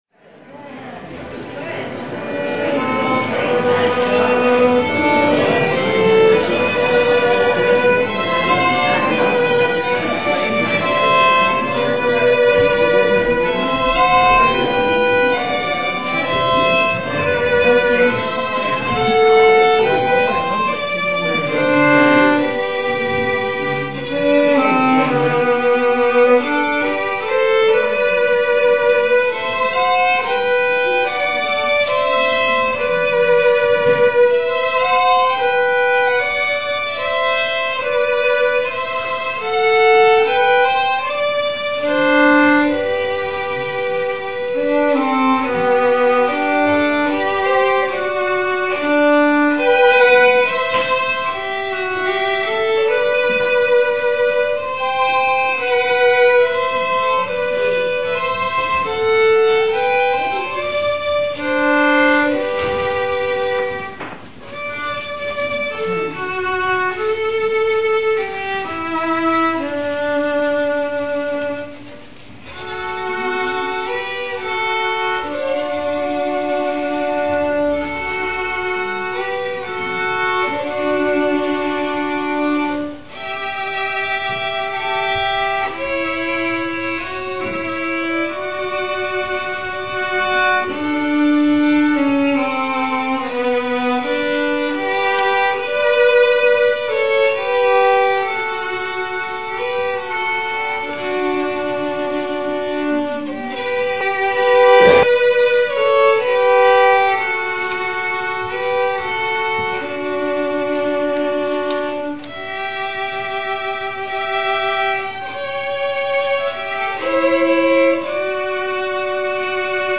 excerpts read
violin duet
Piano / organ duet